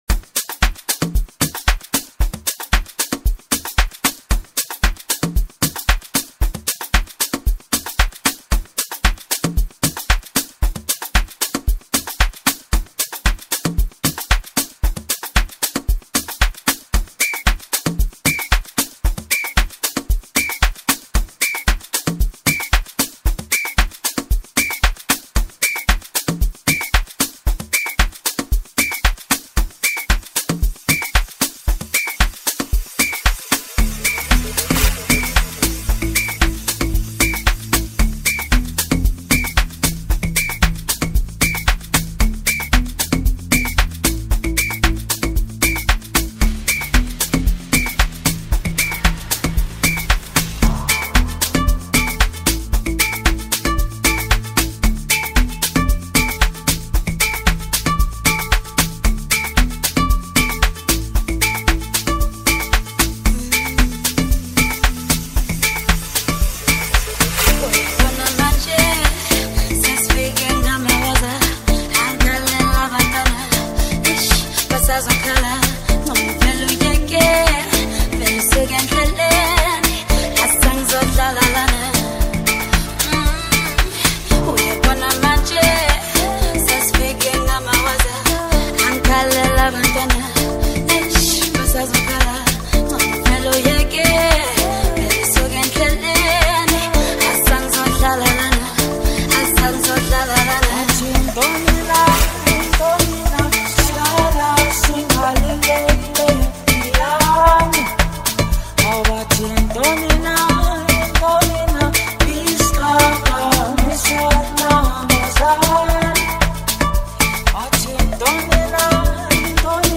beautiful soulful song